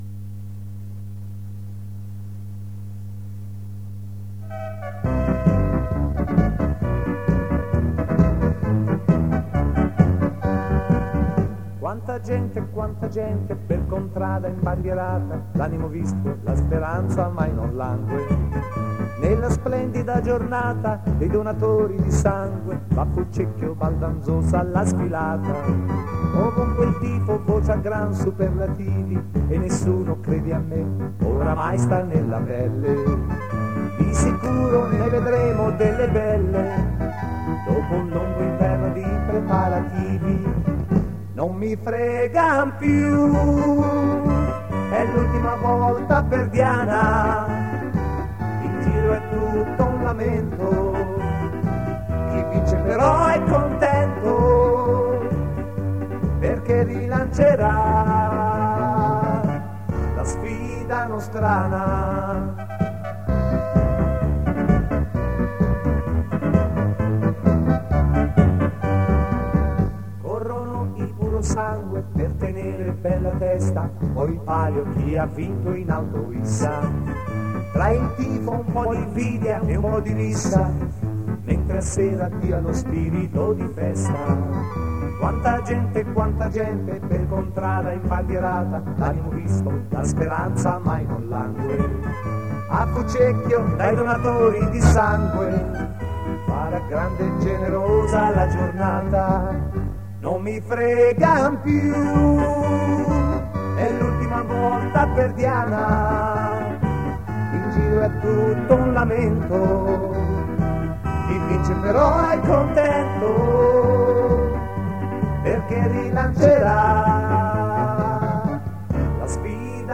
Negli archivi del Gruppo è ancora funzionante la mini cassetta audio
l’inno ufficiale della manifestazione